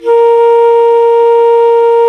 FLT ALTO F0A.wav